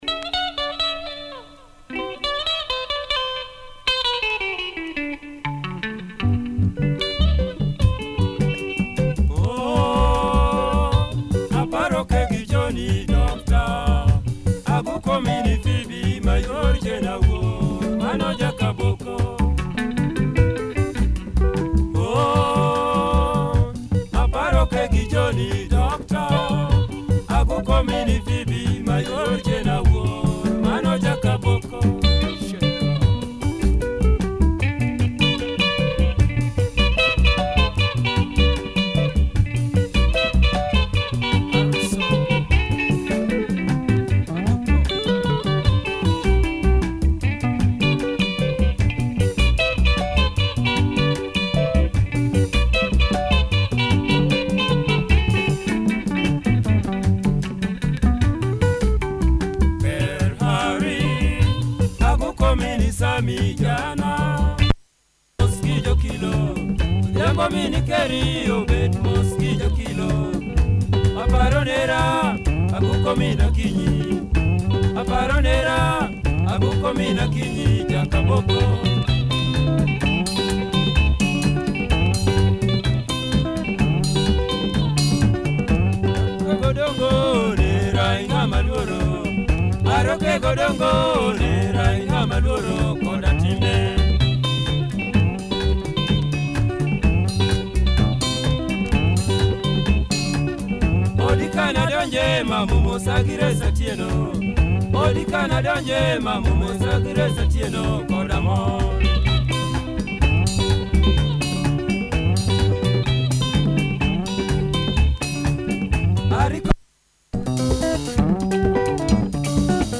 Good early LUO benga !